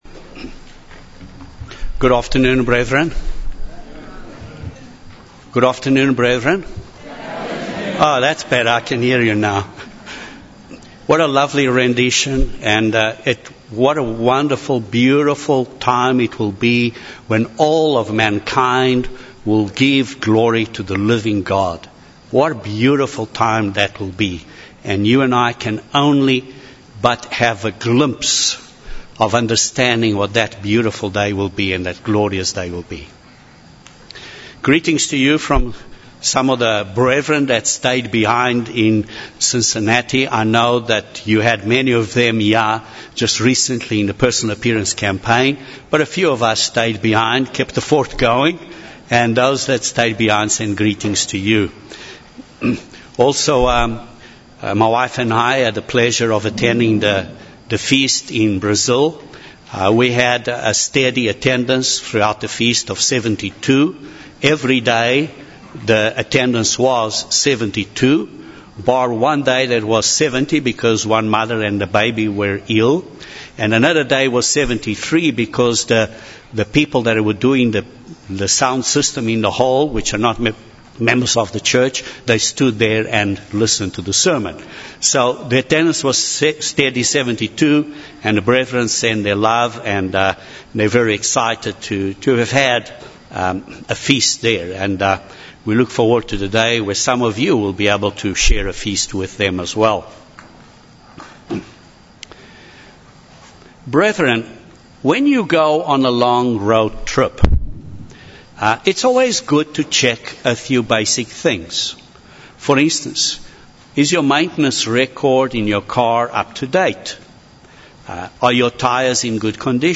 In this sermon we are reminded that the basic doctrines start with repentance from dead works and that involves initially a simple choice between right and wrong, but that choice becomes deeper as we grow in the Church and realize that this choice is a daily mental choice and battle between the works of the flesh and the fruit of the Spirit.